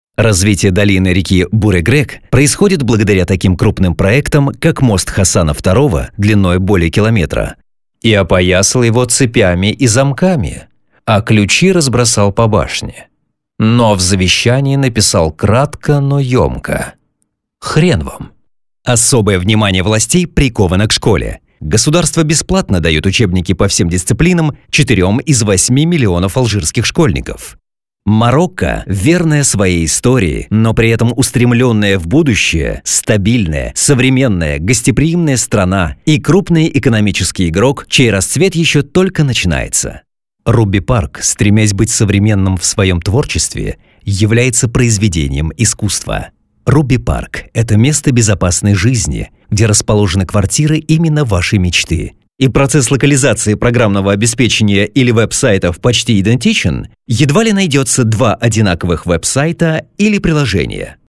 Тракт: rode, akg, shure, tlaudio, dbx